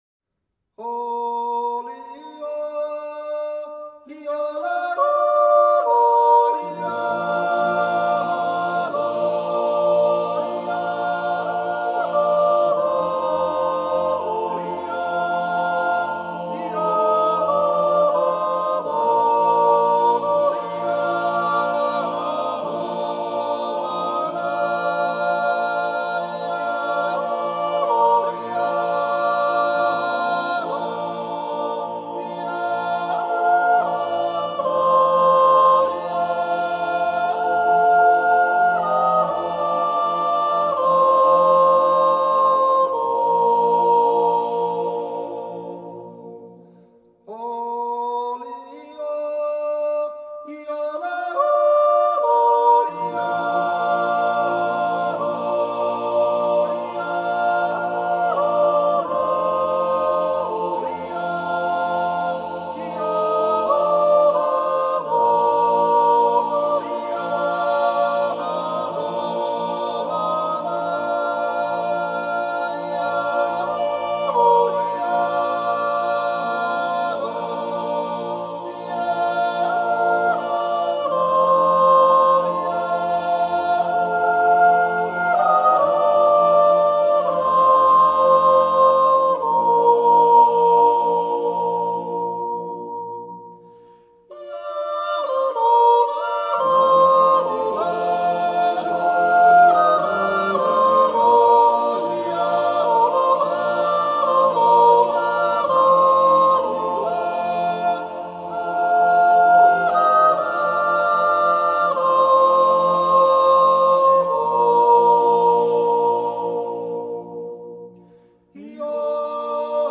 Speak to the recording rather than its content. A Swiss National Yodeling Festival